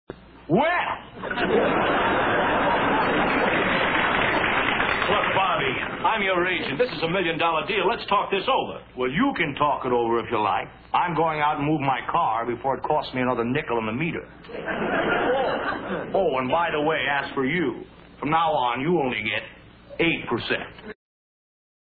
Bobby's impresson of Jack Benny.